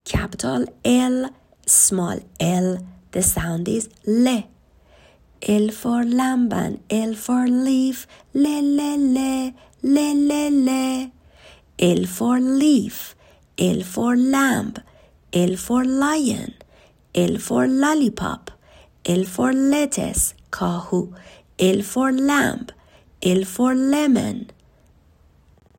حروفی که بچه ها یاد گرفتند تا اینجا رو در قالب چند ویس ، گذاشتم.
حرف Ll ، صداش و لغاتش